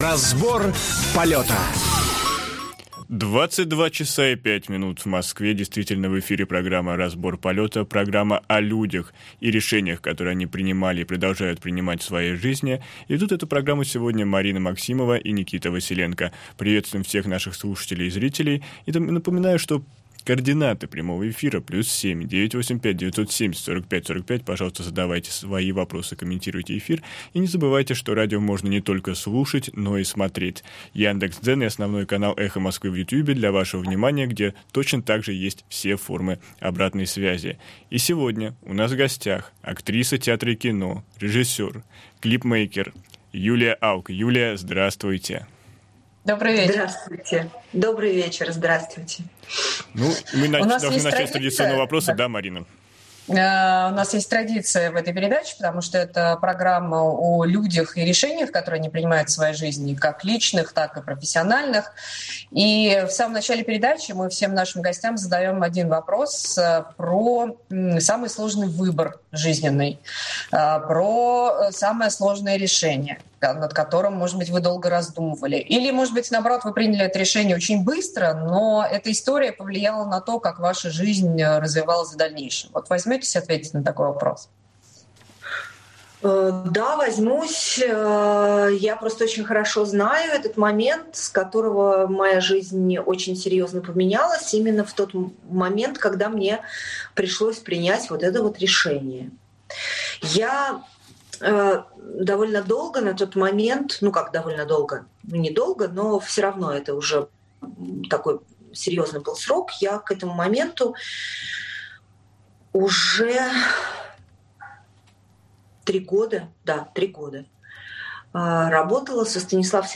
И сегодня у нас в гостях актриса театра и кино, режиссер, клипмейкер Юлия Ауг.